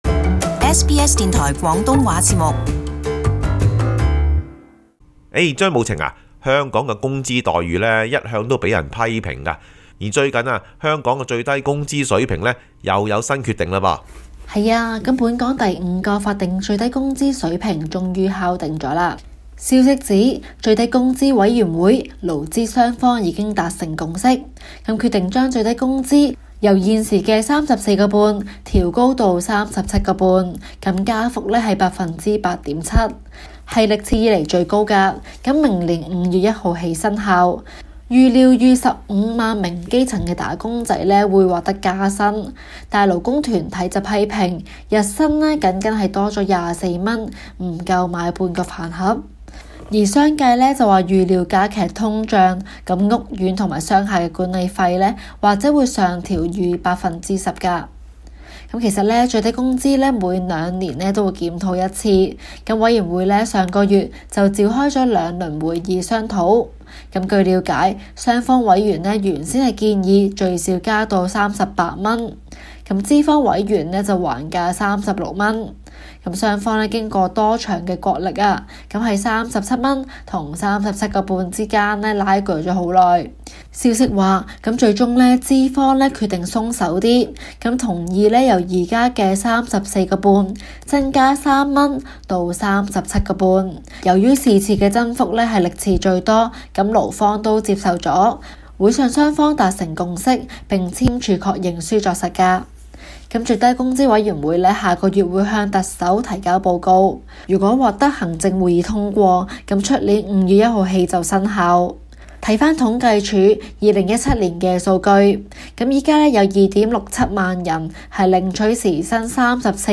【中港快訊】加人工!